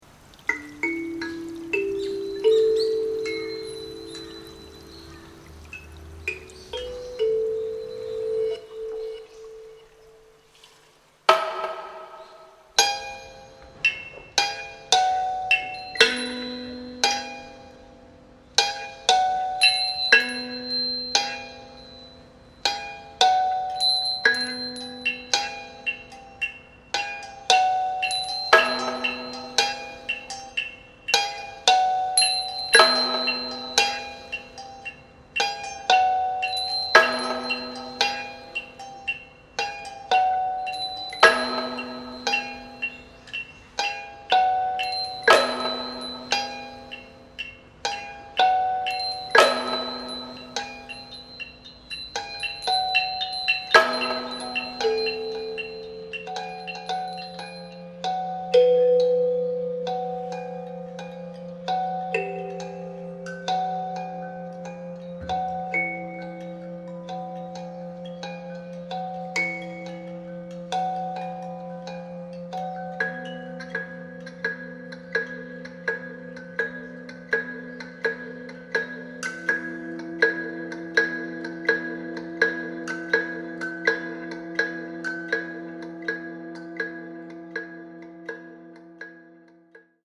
アコーディオン
コントラバス